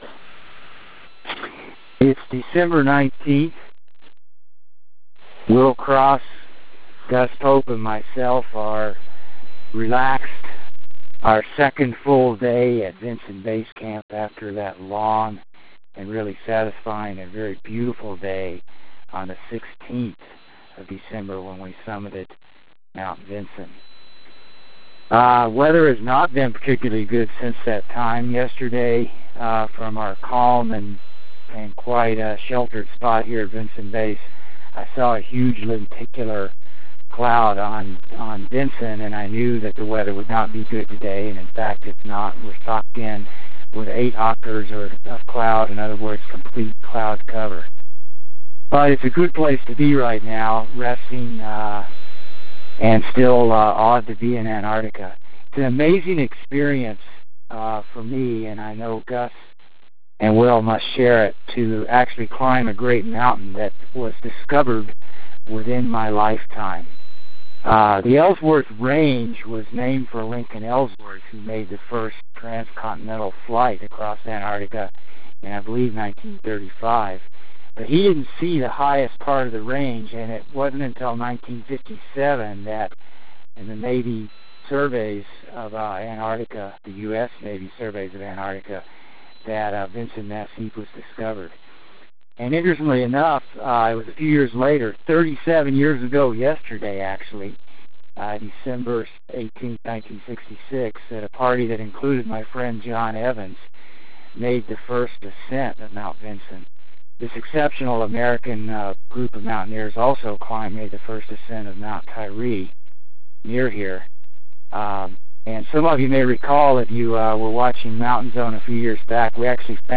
December 19 – Weather changing at Vinson Base